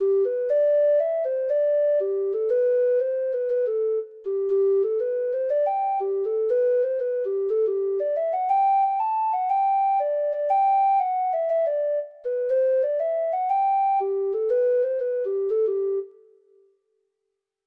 Traditional Sheet Music